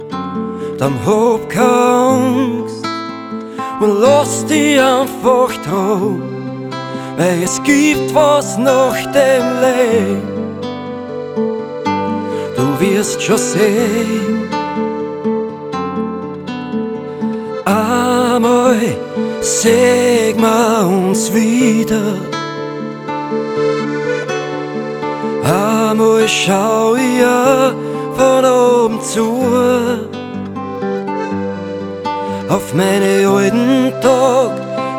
Жанр: Рок / Фолк